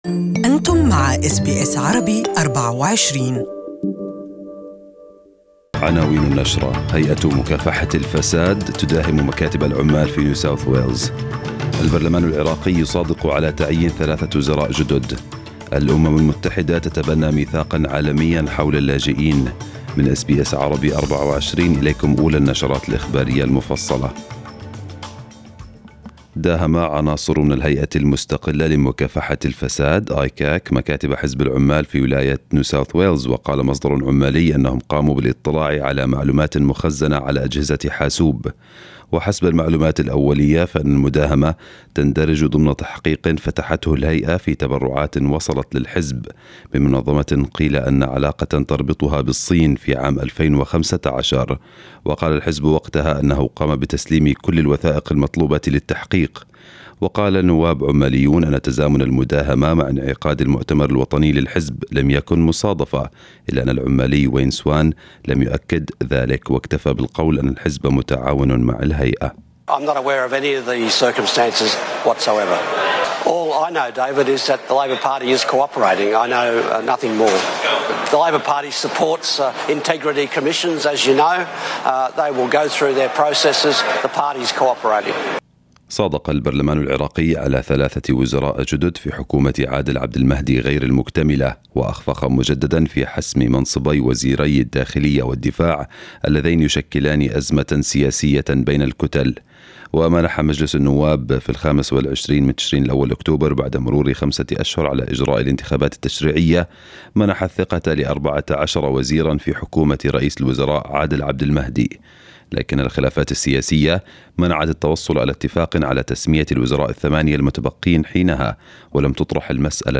News bulletin of the day